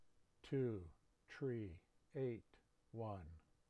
Example: the number 2381 will be spoken as, TOO, TREE, AIT, WUN.